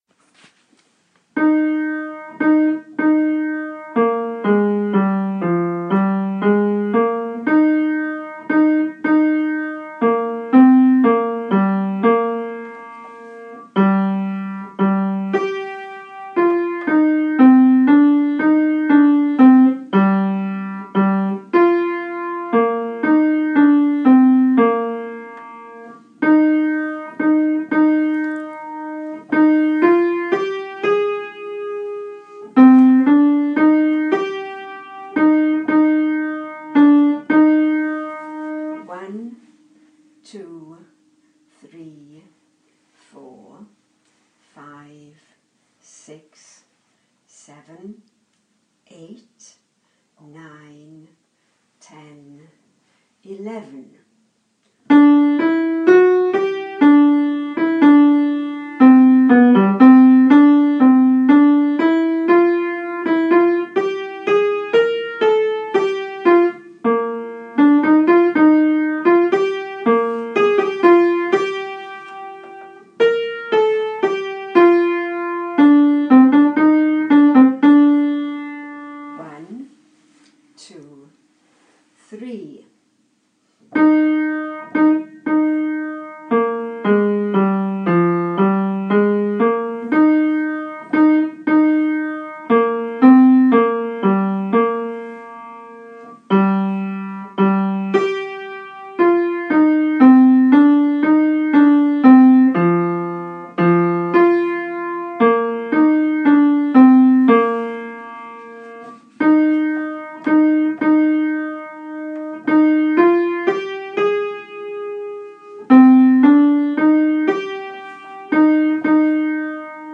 NAME OF SONG TOP TENOR SECOND TENOR BARITONE BOTTOM BASS